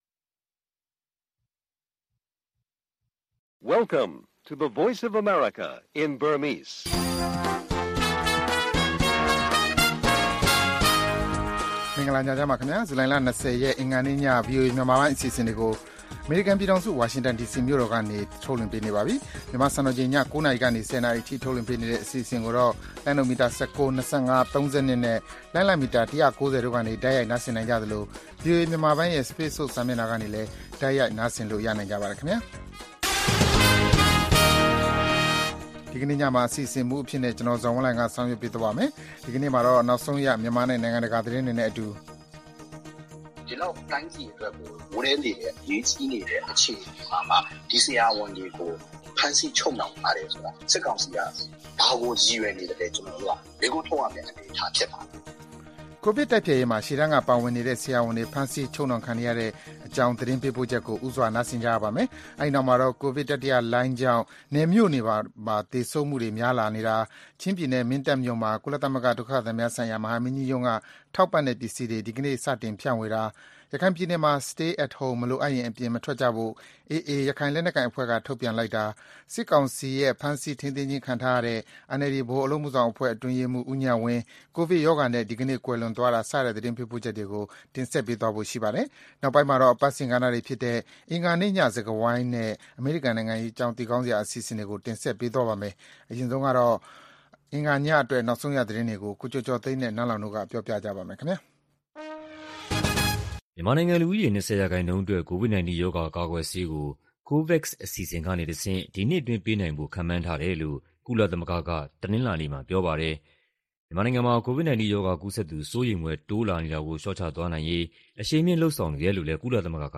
VOA ရေဒီယိုညပိုင်း ၉း၀၀-၁၀း၀၀ တိုက်ရိုက်ထုတ်လွှင့်မှု